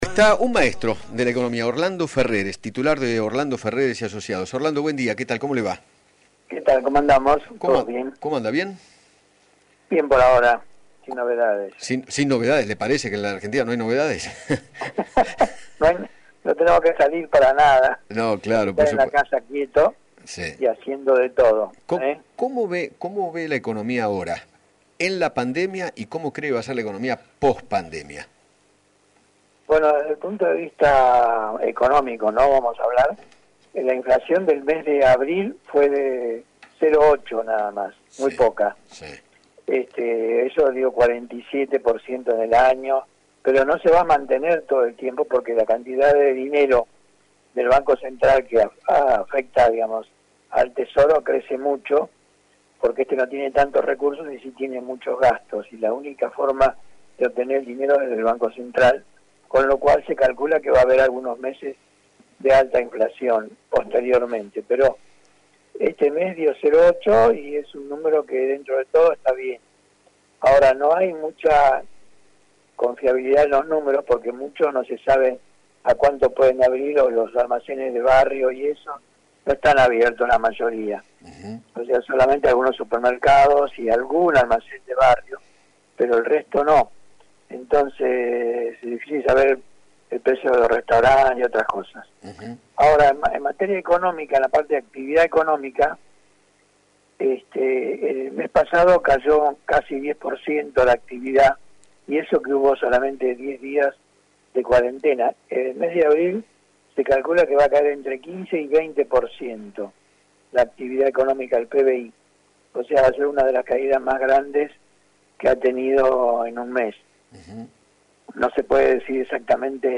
Orlando Ferreres, economista y titular de la consultora Orlando Ferreres & Asociados, dialogó con Eduardo Feinmann sobre la situación económica actual y analizó cómo podría estar después de la cuarentena. Además, se refirió al acuerdo del Gobierno con los bonitas para evitar el default.